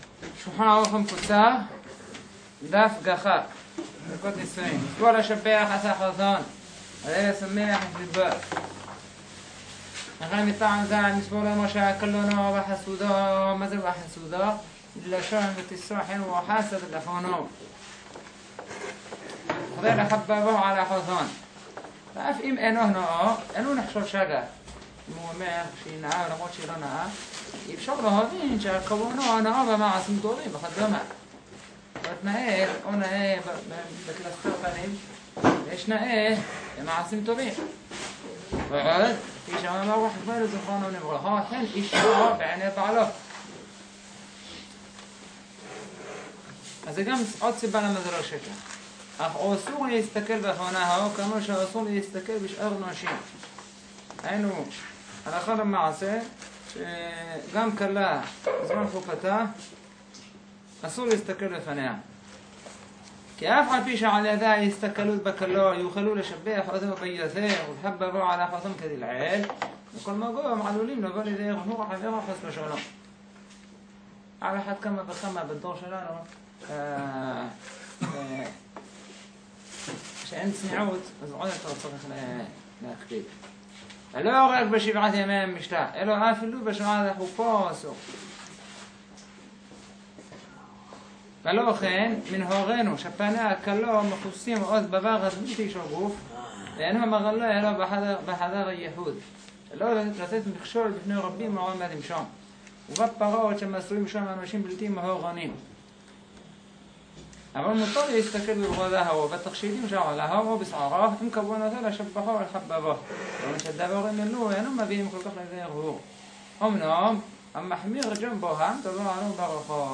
נמסר במסגרת השיעור המקדים